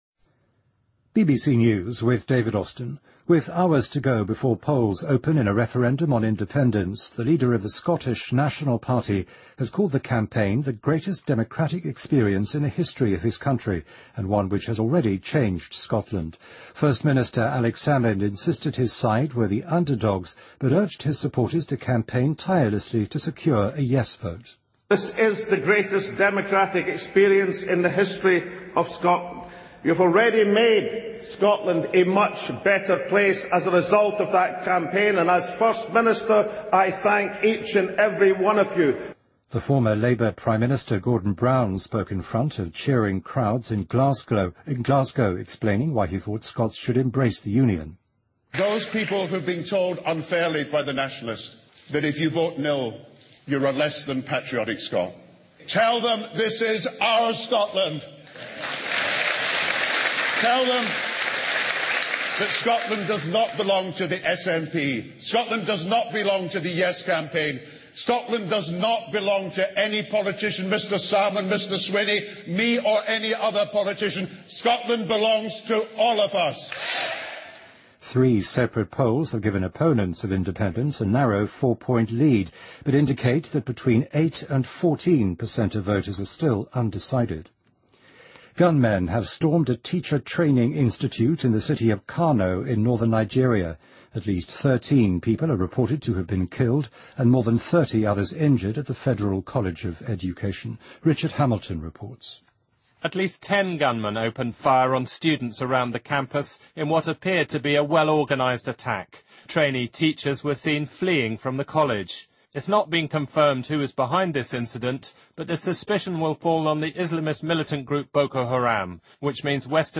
BBC news,美联储宣布对其量化宽松政策做进一步的削减